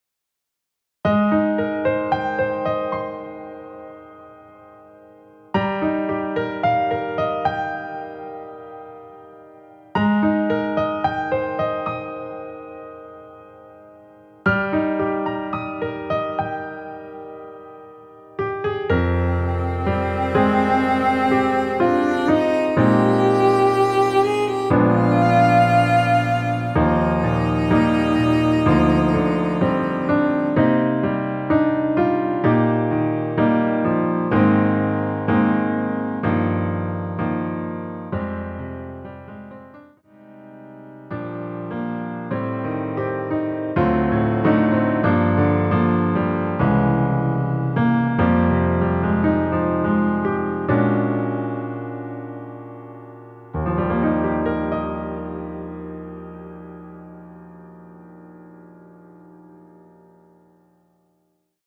엔딩이 페이드 아웃이라 라이브 하시기 좋게 엔딩을 만들어 놓았습니다.(미리듣기 참조)
Eb
앞부분30초, 뒷부분30초씩 편집해서 올려 드리고 있습니다.
중간에 음이 끈어지고 다시 나오는 이유는